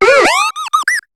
Fichier:Cri 0425 HOME.ogg — Poképédia
Cri de Baudrive dans Pokémon HOME.